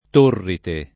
vai all'elenco alfabetico delle voci ingrandisci il carattere 100% rimpicciolisci il carattere stampa invia tramite posta elettronica codividi su Facebook Torrite [ t 1 rrite ] top. (Tosc.) — villaggio nella valle della Turrite Secca